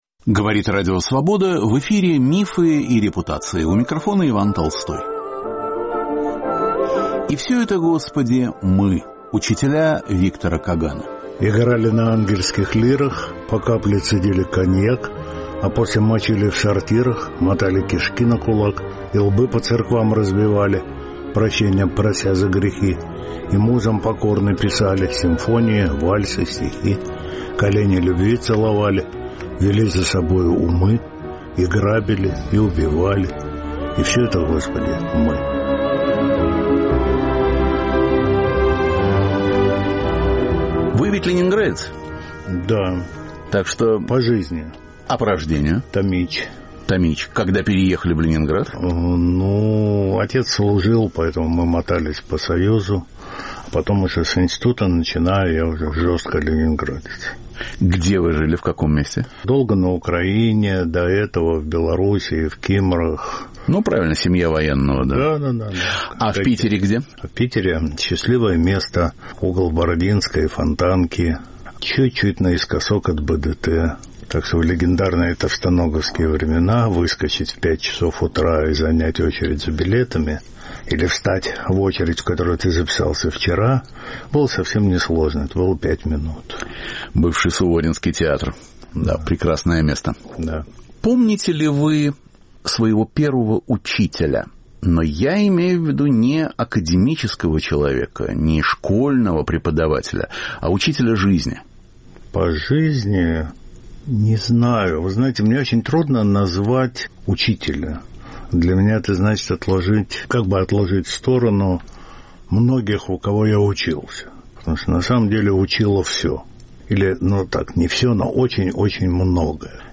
Звучат воспоминания и стихи в исполнении автора.